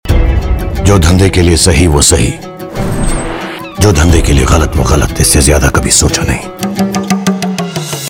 Dialogue Mp3 Tone